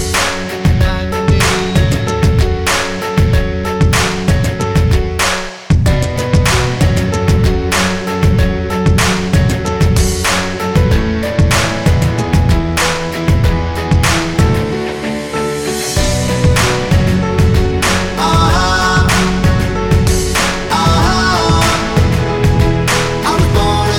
no Backing Vocals R'n'B / Hip Hop 4:01 Buy £1.50